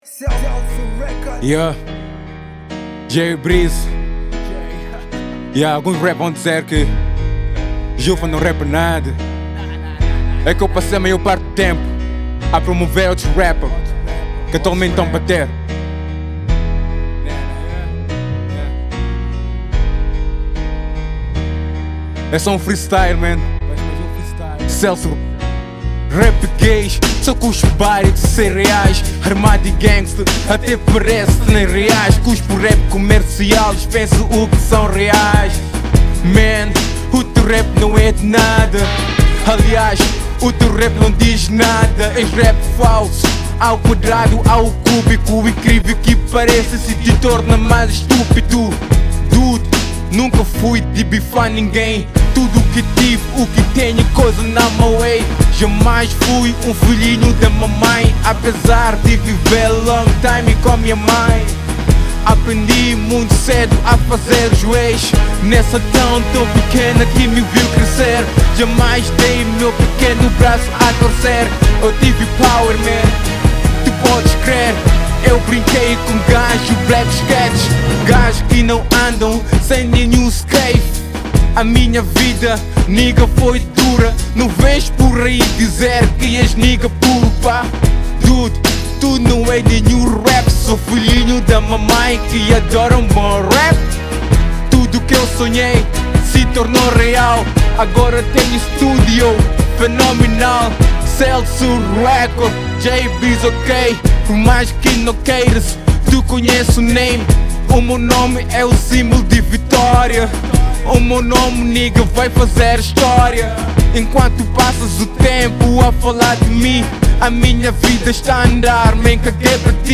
Género: Hip-hop/Rap